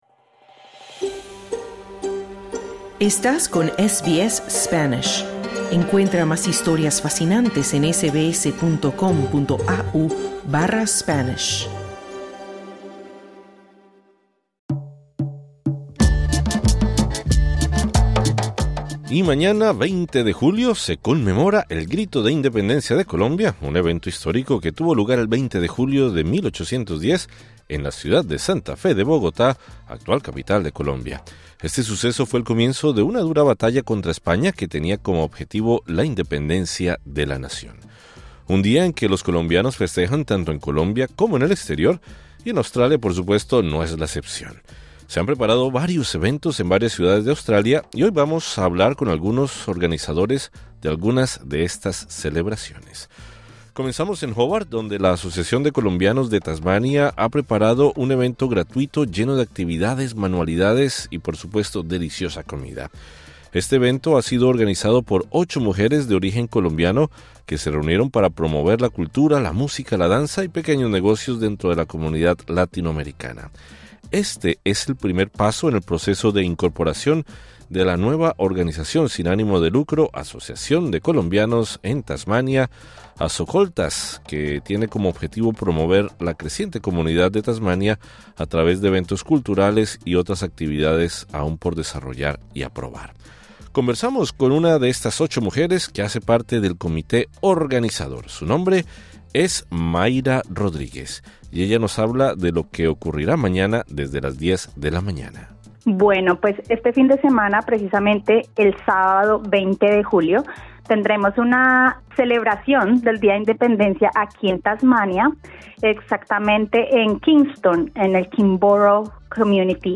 El 20 de julio se conmemora el Día de Independencia de Colombia y en varias ciudades de Australia se están organizando eventos de celebración. Hablamos con organizadores de Tasmania, Territorio del Norte y Victoria.